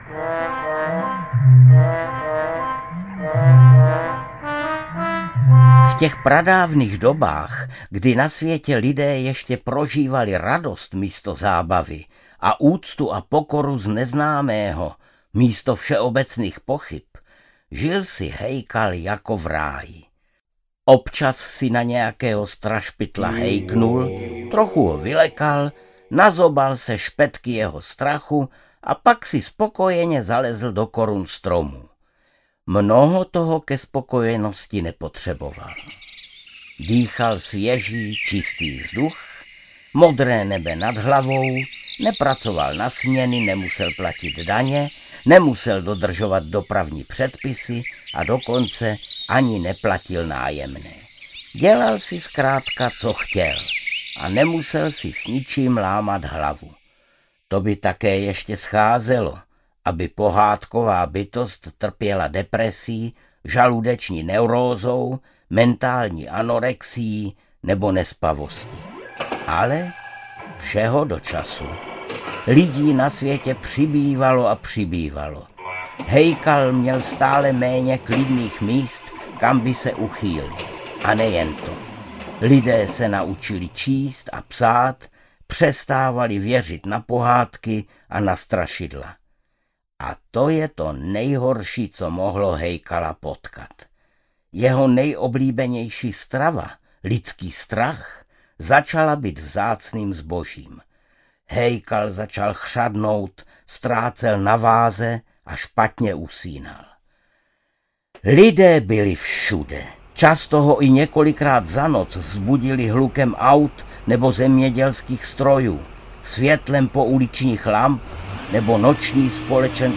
Ptačí pohádky - Proč má kos žlutý nos? audiokniha
Třináct krásných pohádek z ptačího světa. Nechte sebe a vaše děti okouzlit příběhy z ptačího světa, v jedinečném podání předního českého herce, Arnošta Goldflama.
Ukázka z knihy